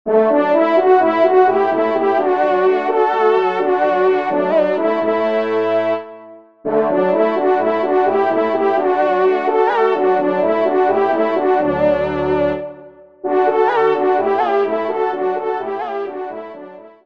Trio Trompes  (Ton de vénerie)